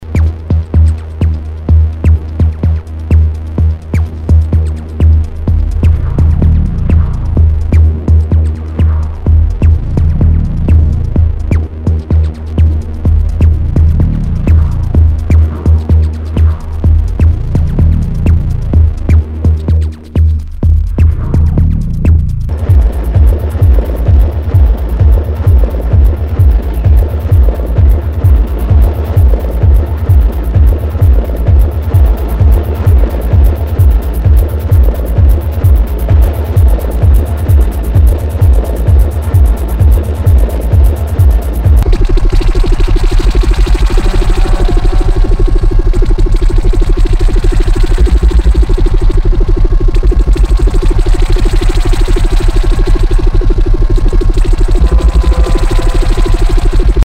HOUSE/TECHNO/ELECTRO
ナイス！IDM / テクノ！！